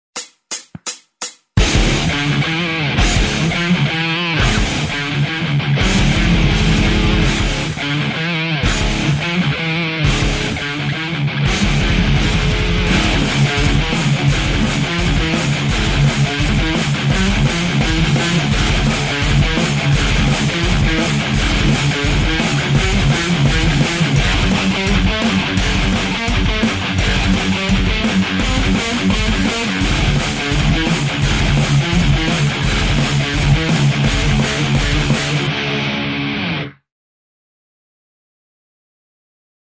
Кусочек ниочёмного какого-то хардкора.